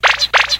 Play, download and share boingggg original sound button!!!!
boingggg.mp3